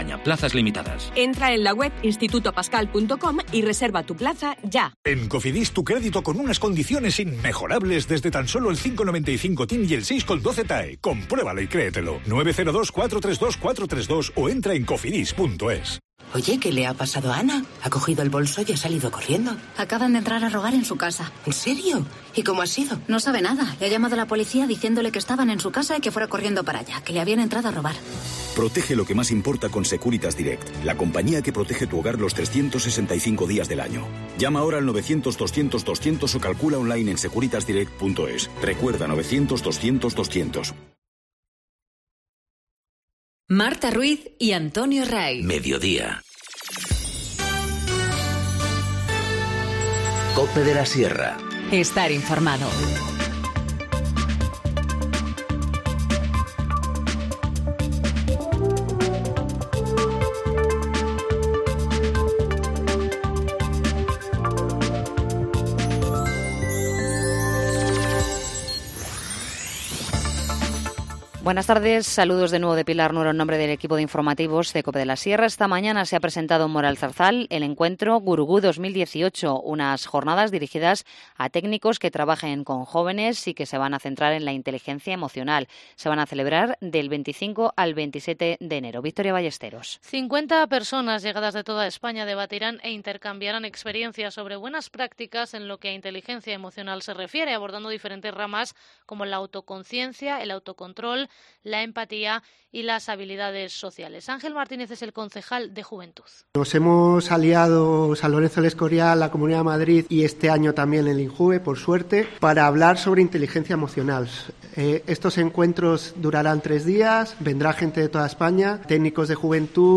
Informativo Mediodía 12 dic- 14:50h